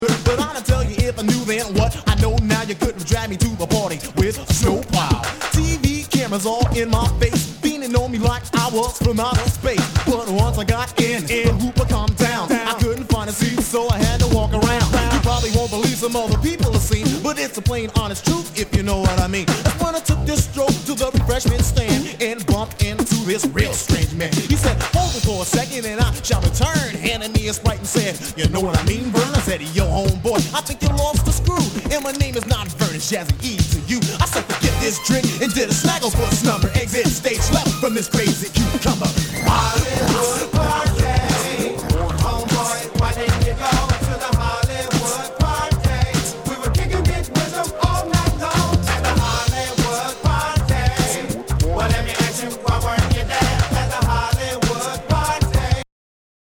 SOUL/FUNK/DISCO
ディスコ・ラッピン！
全体にチリノイズが入ります